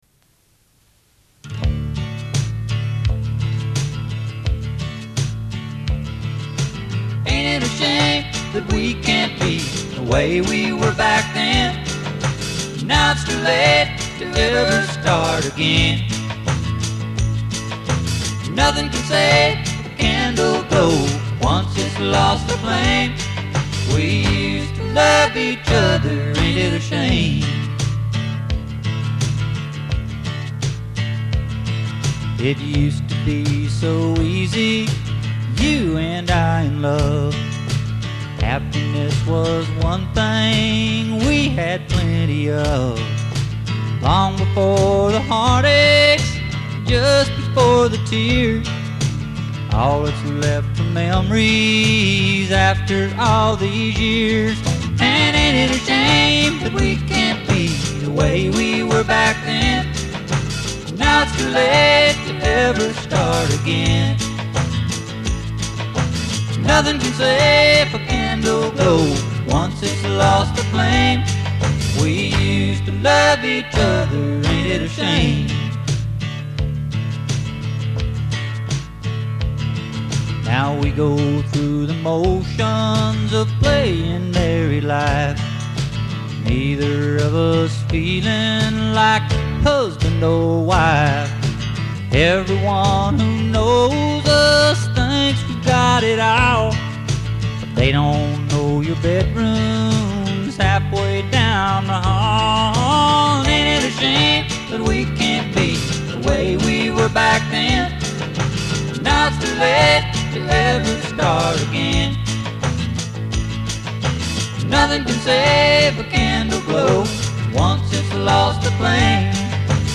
CASSETTE DEMO